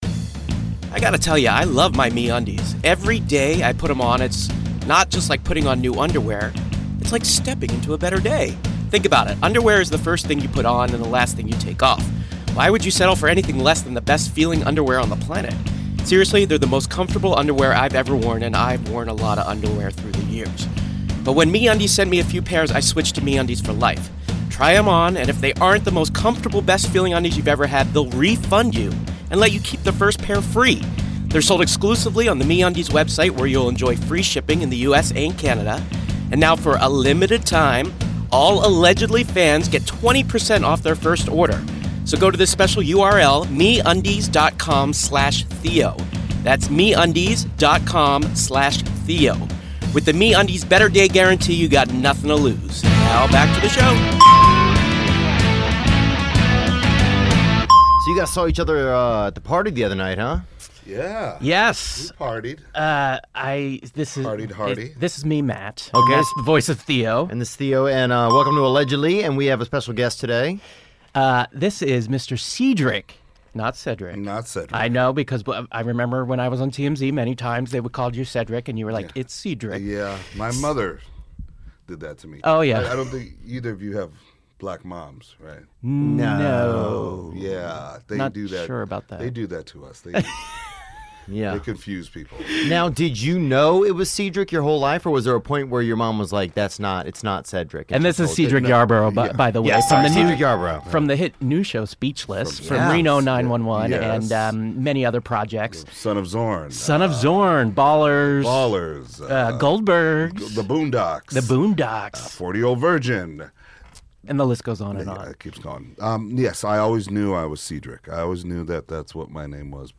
Facebook Twitter Headliner Embed Embed Code See more options You know him from his hit new ABC show "Speechless", and from RENO 911 as Deputy Jones, its Cedric Yarbrough in the 'Allegedly' Studio.